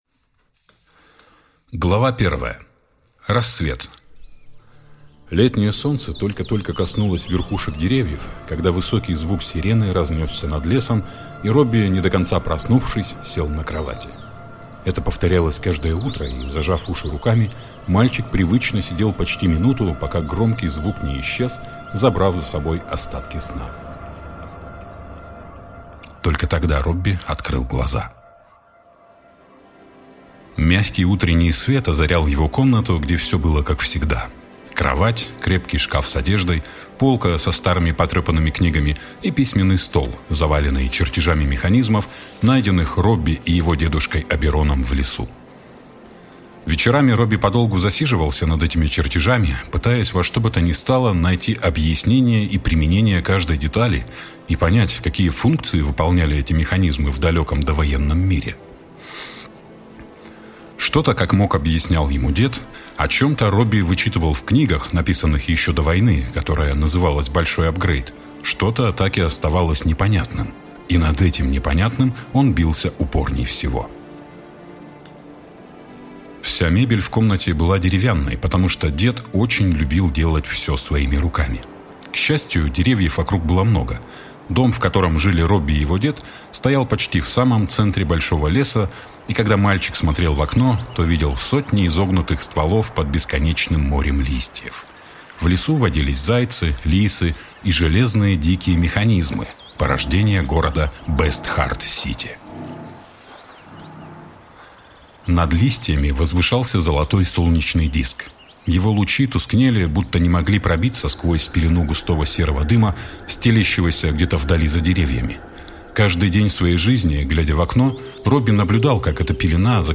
Аудиокнига Хроники разбитого мира. Роби и тайна фриджитов | Библиотека аудиокниг